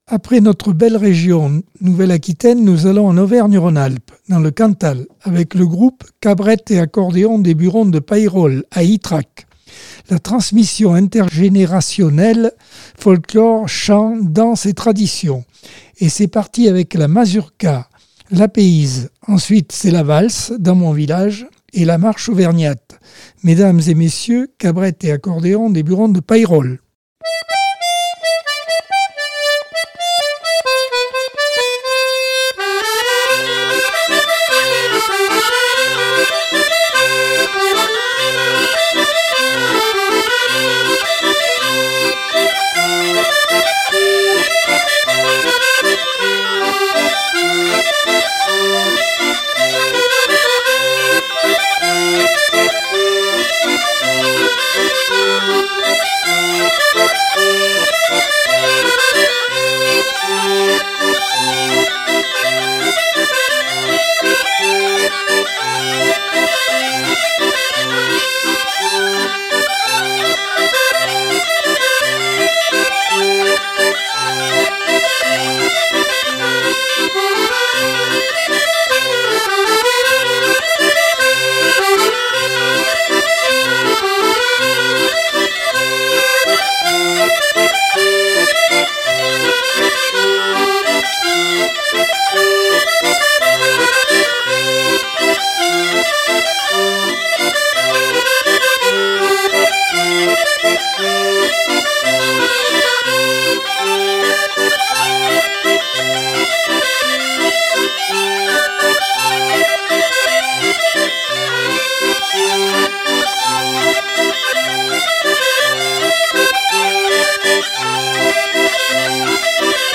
Accordeon 2024 sem 42 bloc 3 - Radio ACX